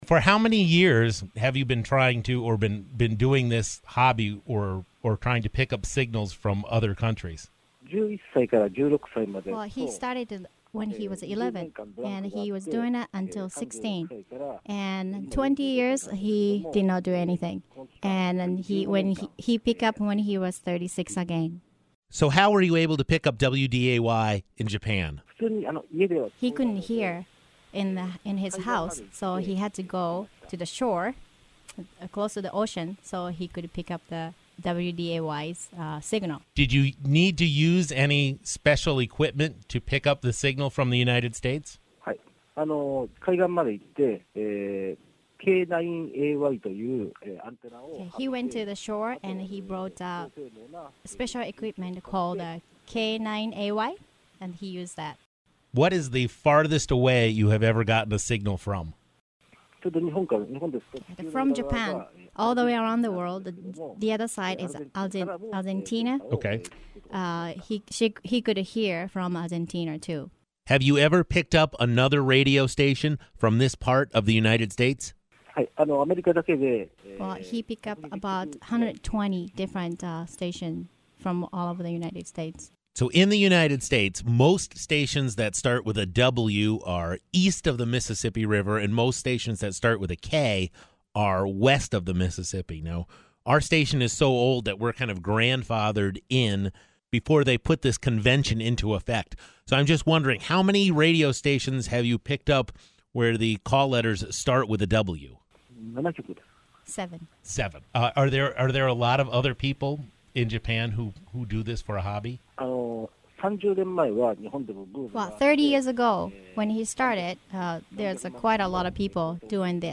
海外のDXerの協力で確認でき、返信が来なかったのに諦めずにフォローアップで返信を貰い、First Japanの言葉を貰い、そしてインタビューで放送に出演した・・・いろいろな意味で一生忘れられない局になりそうだ。
Mixdowncalltojapan.mp3